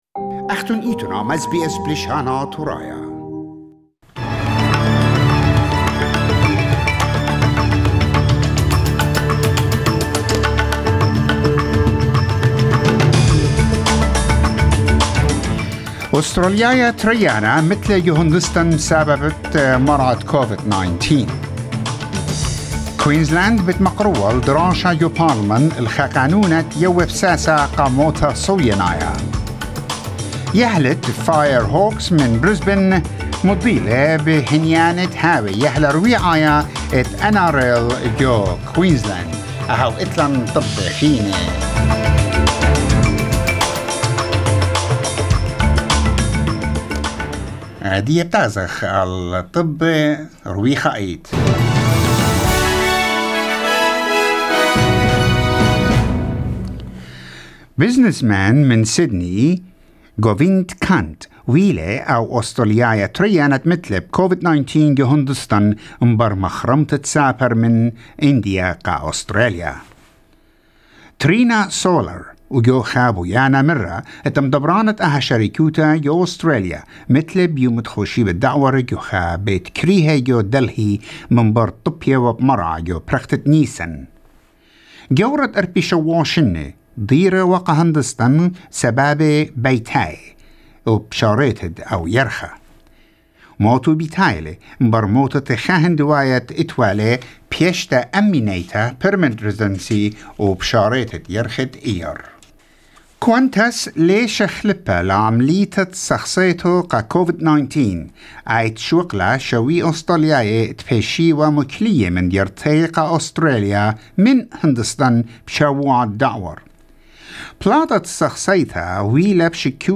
SBS News Bulletin in Assyrian 18 May 2021